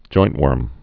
(jointwûrm)